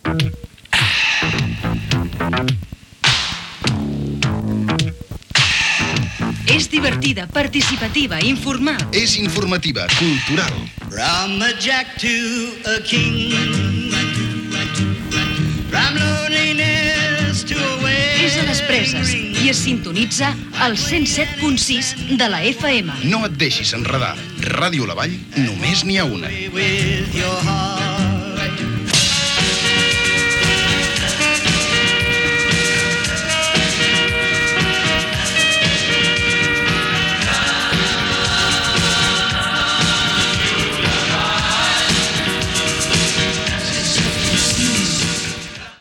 Indicatiu de l 'emissora
Banda FM